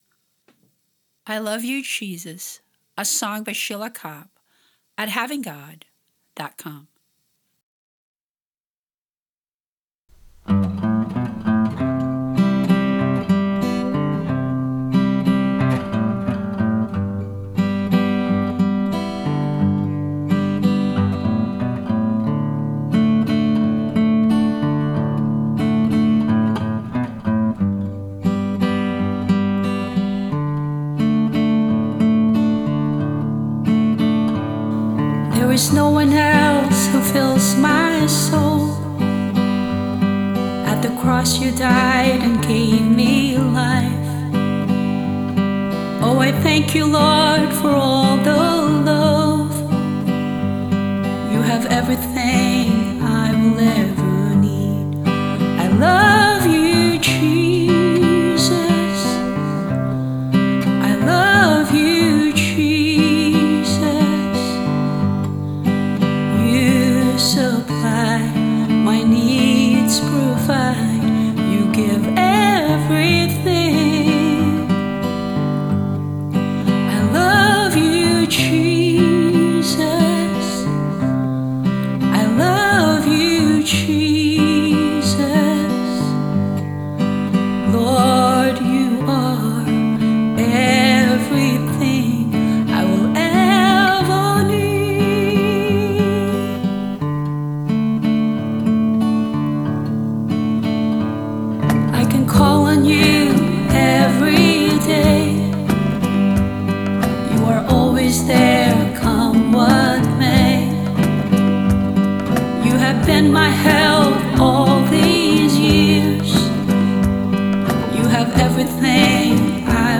Vocals, guitar, bass and drum machine
Organ and piano